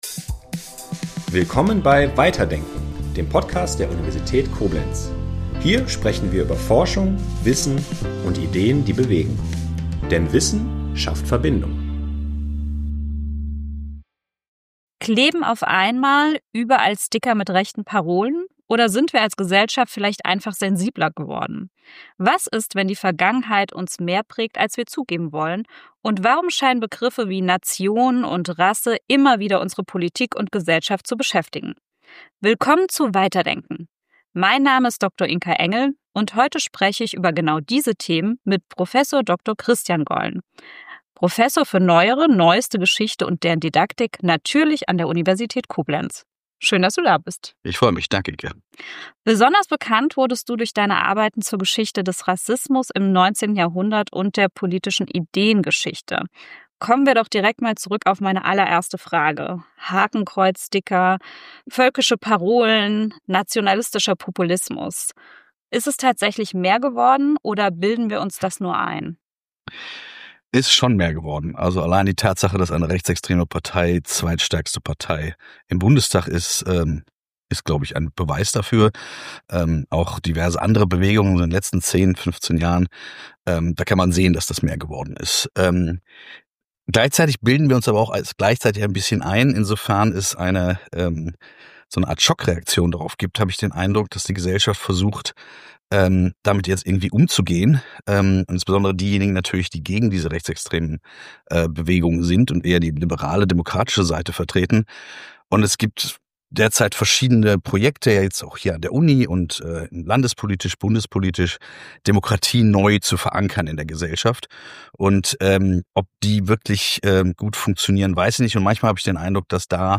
In dieser Folge geht es um die erschreckende Normalisierung rechter Rhetorik, über die Strategien von Trump und der AfD – und darüber, wie Sprache zum Machtinstrument wird. Im Gespräch